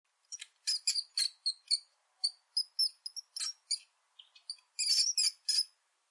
Download Mouse sound effect for free.
Mouse